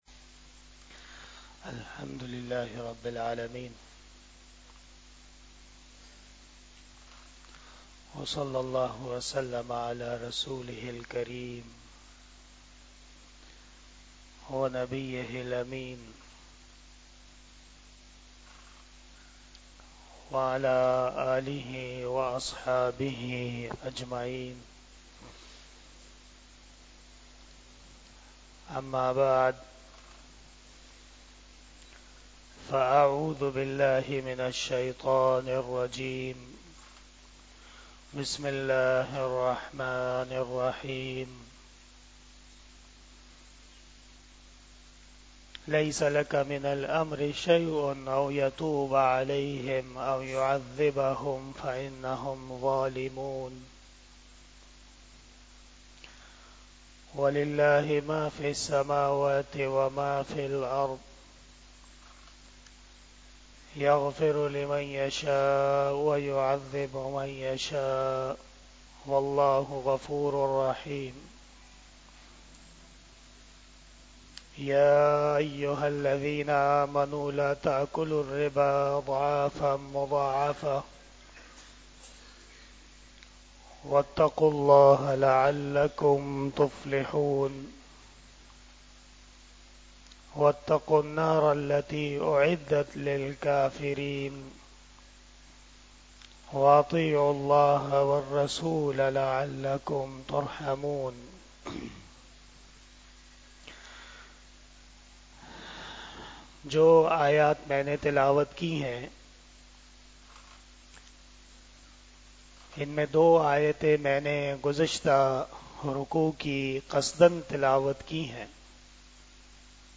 05 Shab E Jummah Bayan 01 February 2024 (21 Rajab 1445 HJ)